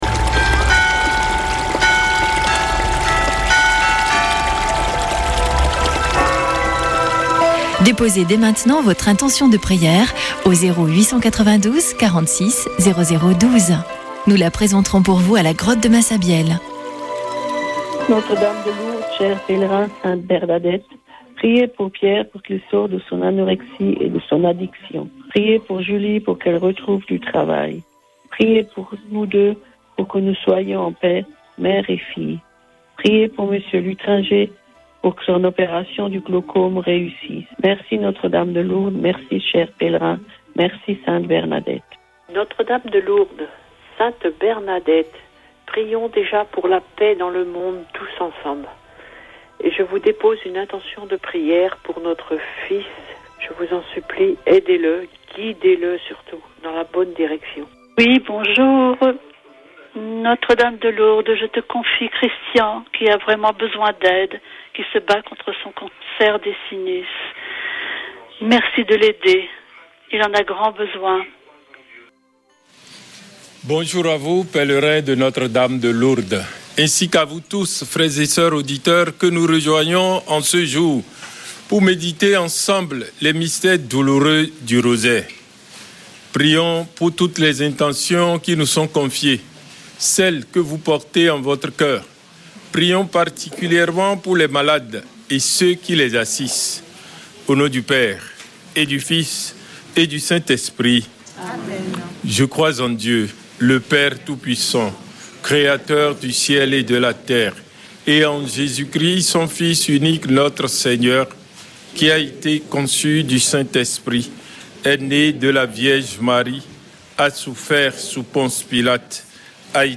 Chapelet de Lourdes du 17 mars
Une émission présentée par Chapelains de Lourdes